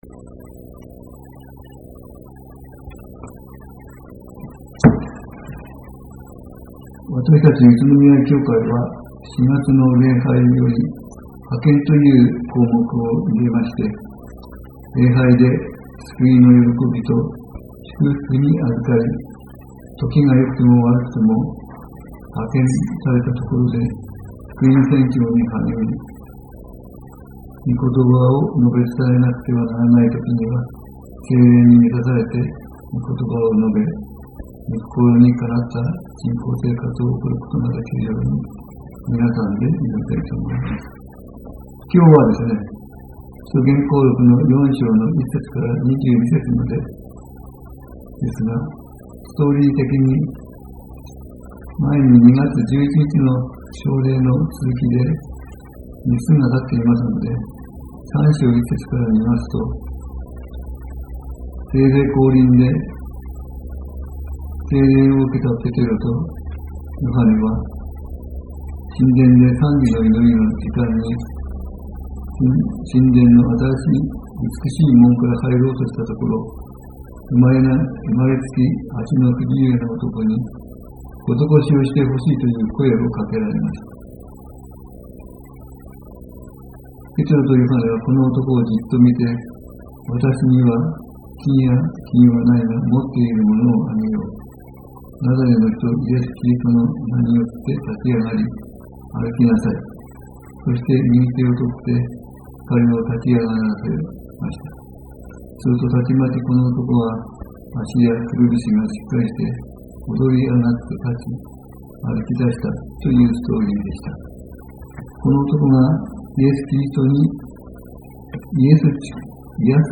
礼拝説教アーカイブ 日曜 朝の礼拝